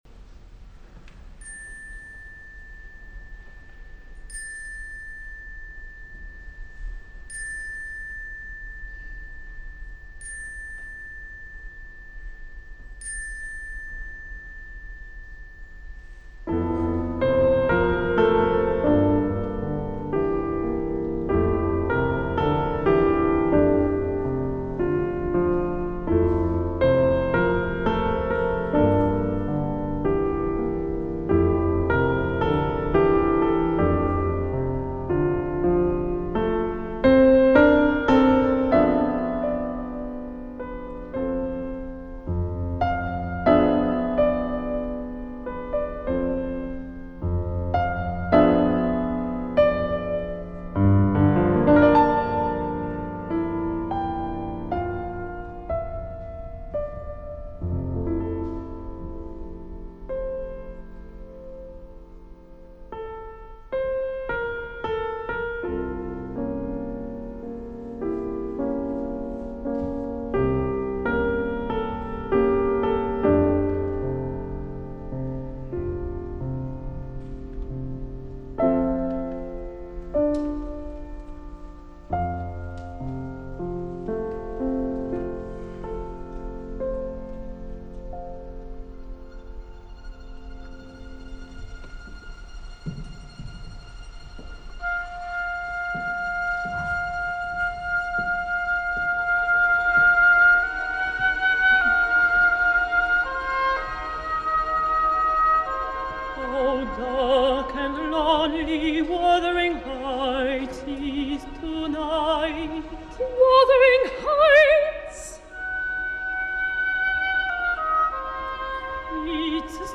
Versió de concert.
Tenor